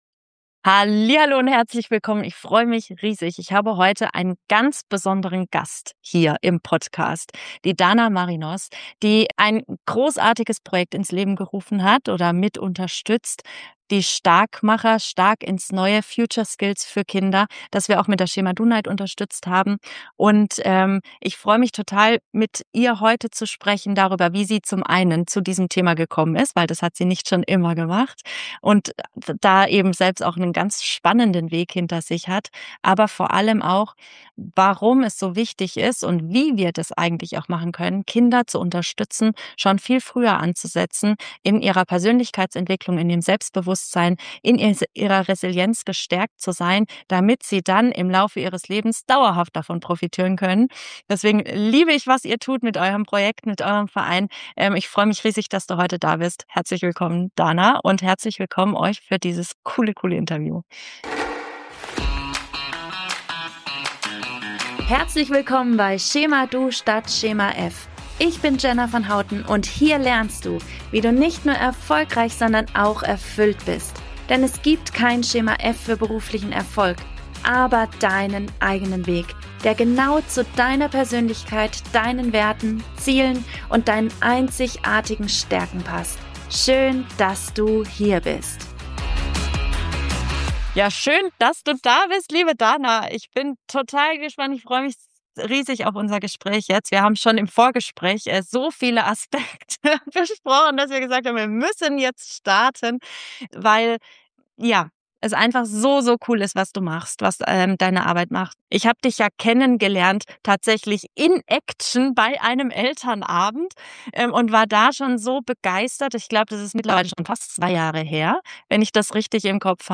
Erfahre in diesem Interview, was der ‚Anruf bei mir selbst‘ bedeutet, wie wir als Eltern und Vorbilder das Wachstum unterstützen können und warum es nie zu spät ist, auch als Erwachsener die eigene Selbstführung und Lebensfreude neu zu entdecken. Wir beleuchten das Zusammenspiel im ‚Team Kind‘ zwischen Eltern und Pädagogen sowie neue Ansätze zur finanziellen Bildung für Kids.